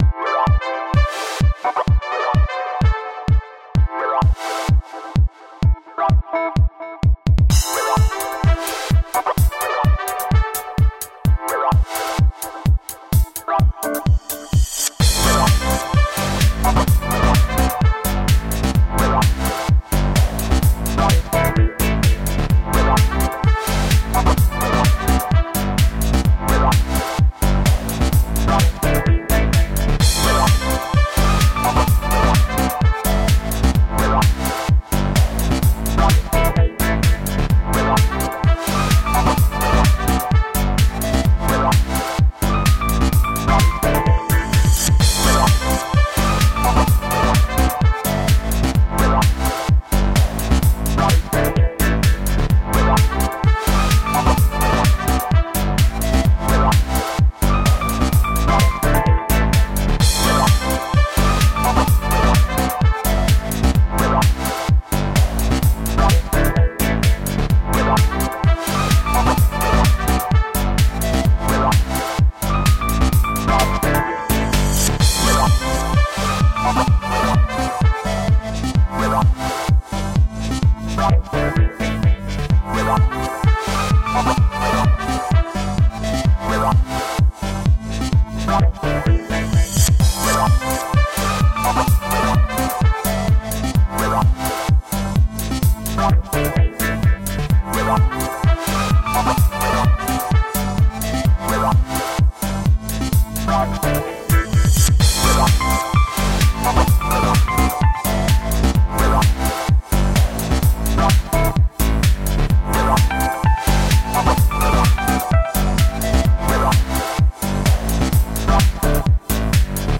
Tagged as: Electronica, Pop, Chillout, Happy Hour